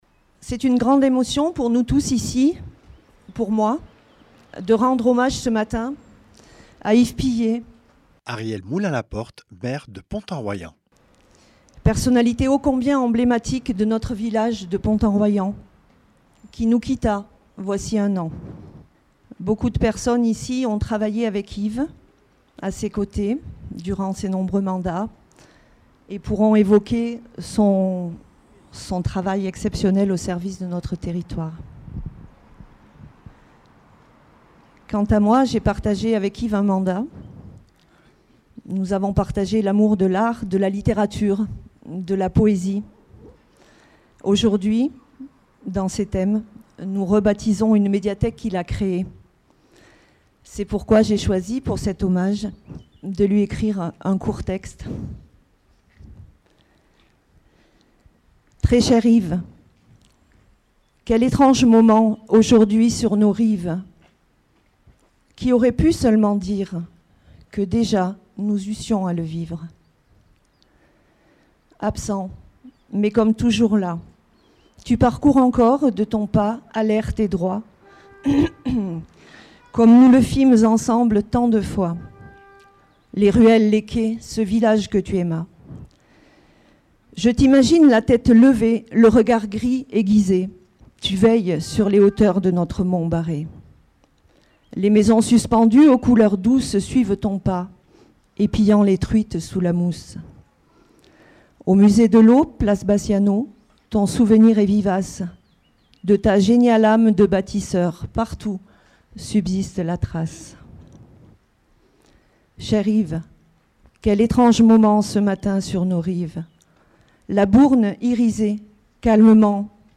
De multiples élu-e-s, resp. associatif et famille se sont succédé-e-s au micro afin de lui rendre hommage.